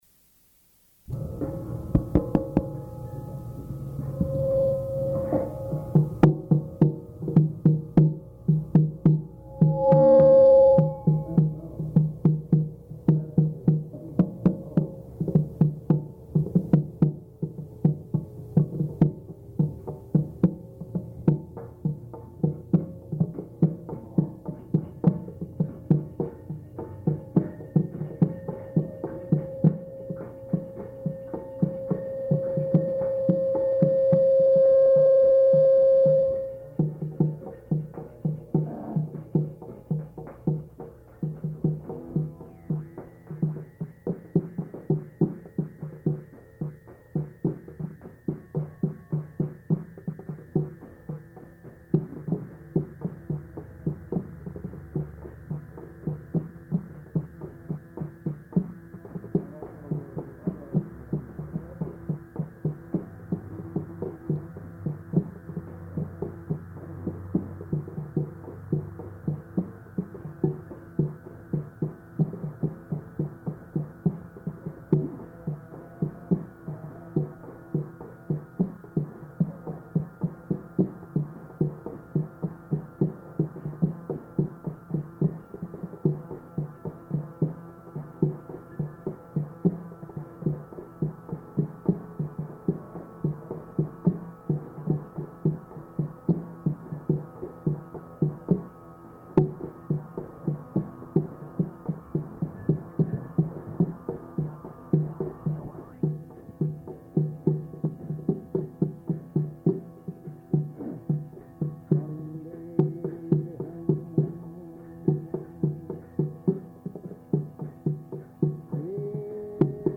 Location: New York
[Poor Audio]
[taps on microphone]